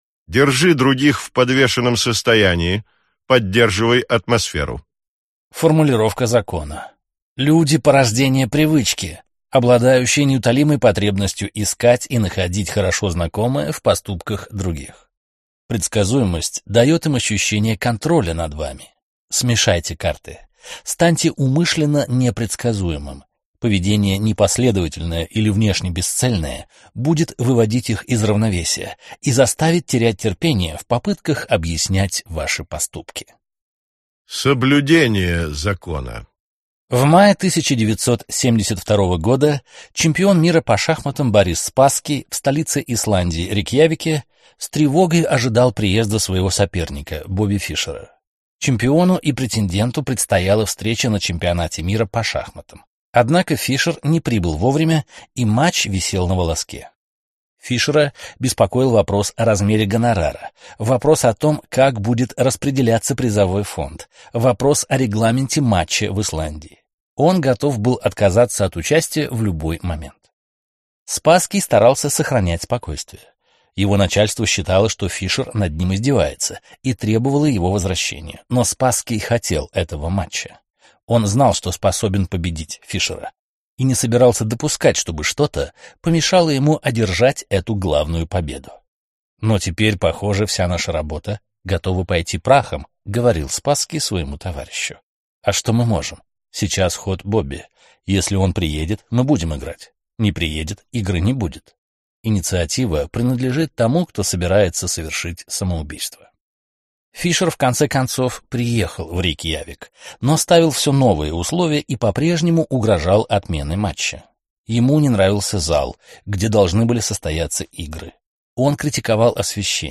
Аудиокнига 48 законов власти. Законы 17-32 | Библиотека аудиокниг